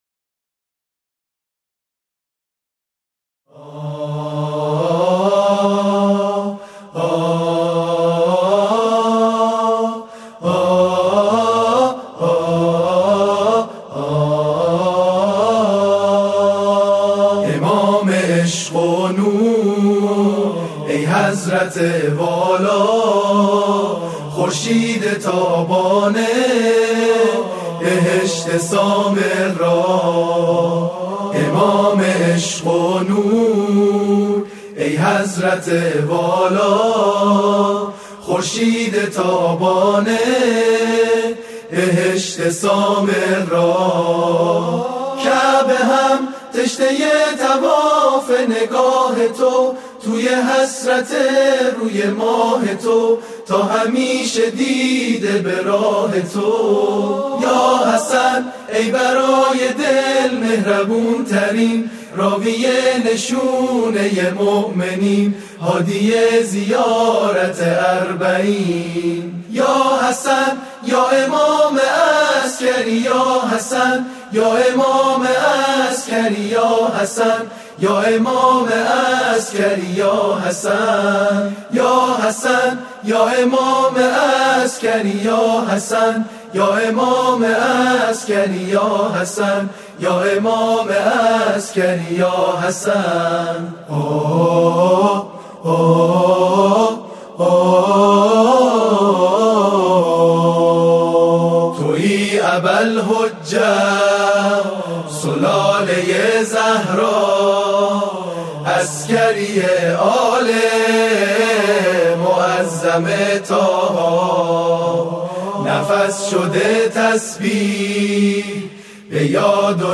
قطعه مذهبی